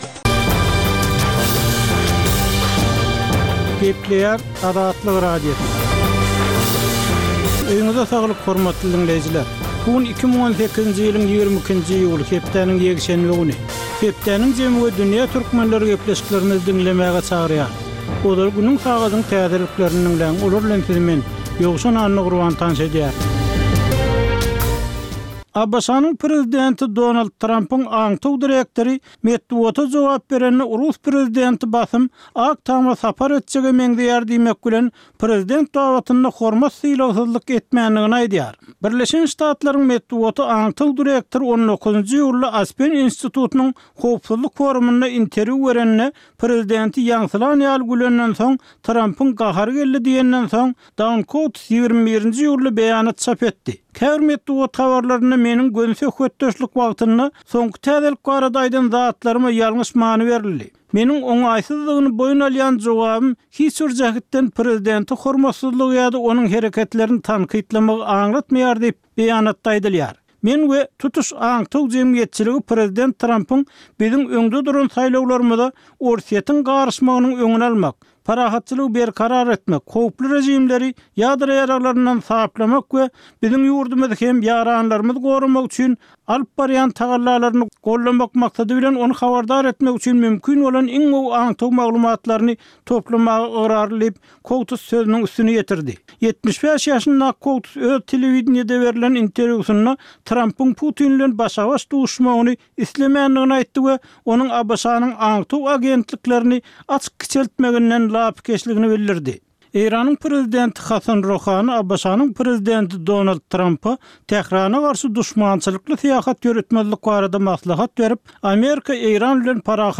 Tutuş geçen bir hepdäniň dowamynda Türkmenistanda we halkara arenasynda bolup geçen möhüm wakalara syn. Bu ýörite programmanyň dowamynda hepdäniň möhüm wakalary barada synlar, analizler, söhbetdeşlikler we kommentariýalar berilýär.